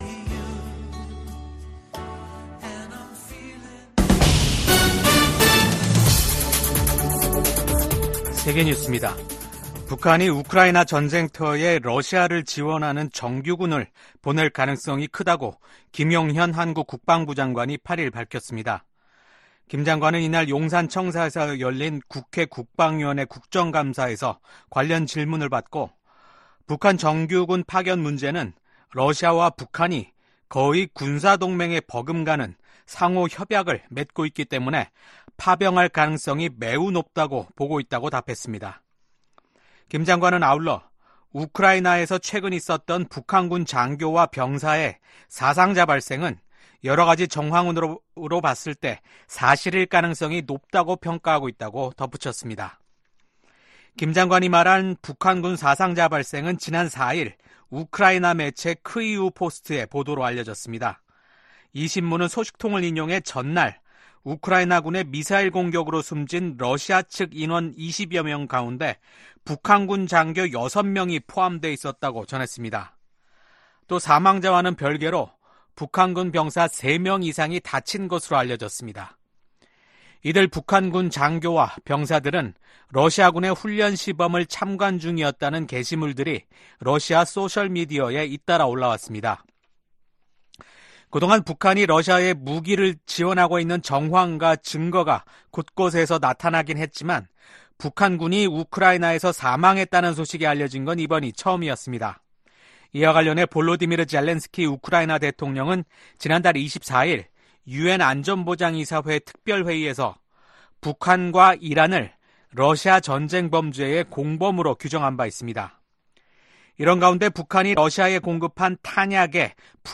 VOA 한국어 아침 뉴스 프로그램 '워싱턴 뉴스 광장' 2024년 10월 9일 방송입니다. 김정은 북한 국무위원장은 적들이 무력 사용을 기도하면 주저없이 핵무기를 사용할 것이라고 위협했습니다. 미국 정부가 북한 해킹조직 라자루스가 탈취한 가상 자산을 압류하기 위한 법적 조치에 돌입했습니다.